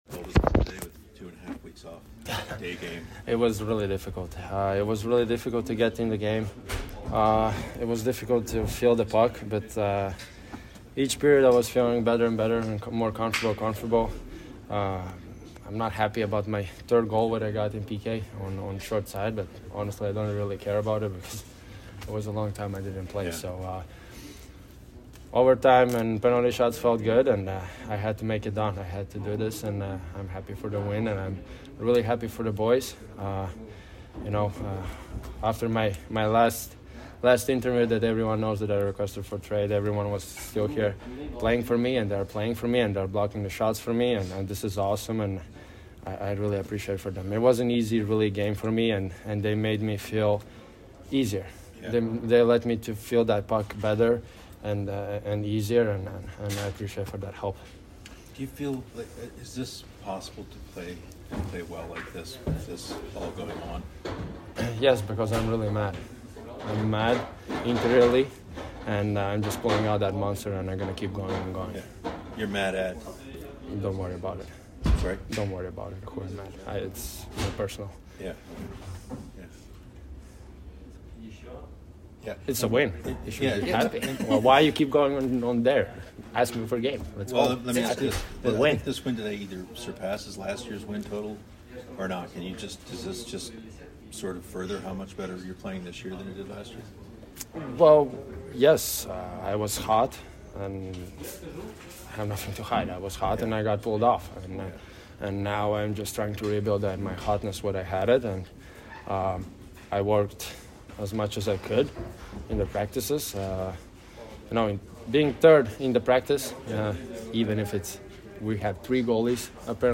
BLUE JACKETS POST-GAME AUDIO INTERVIEWS